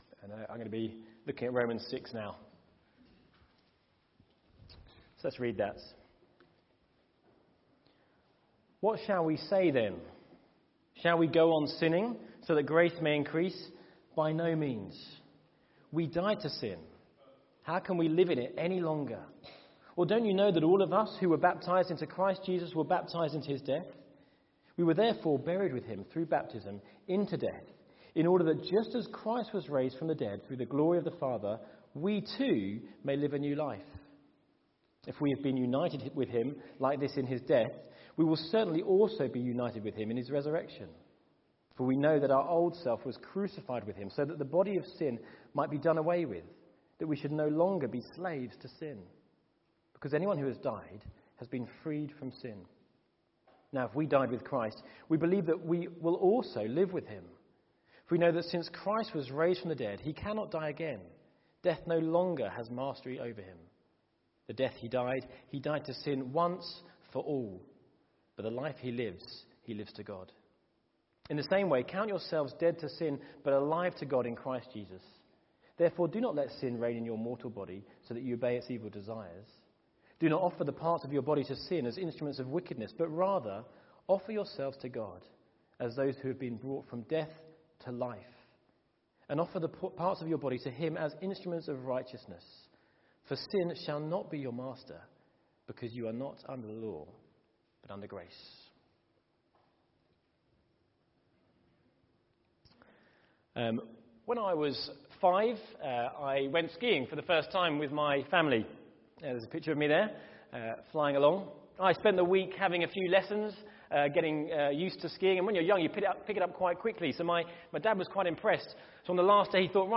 Baptismal Service – September 2018